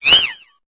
Cri de Chacripan dans Pokémon Épée et Bouclier.